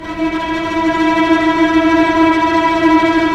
Index of /90_sSampleCDs/Roland - String Master Series/STR_Vcs Tremolo/STR_Vcs Trem p